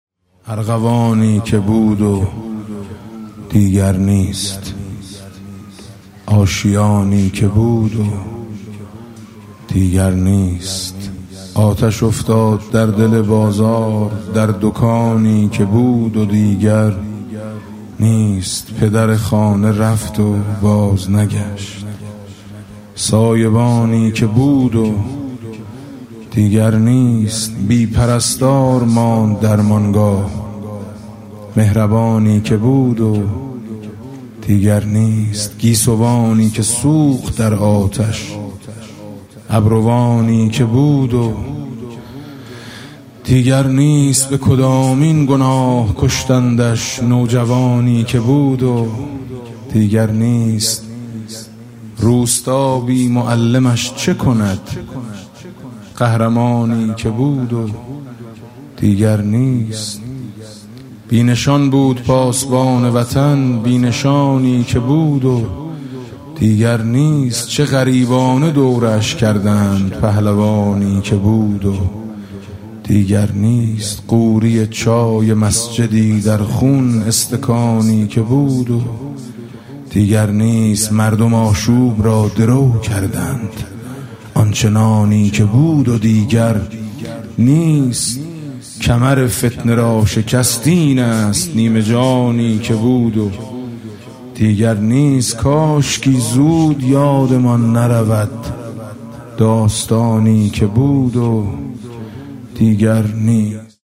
[آستان مقدس امامزاده قاضي الصابر (ع)]
مناسبت: قرائت دعای کمیل در شب نیمه‌ شعبان
با نوای: حاج میثم مطیعی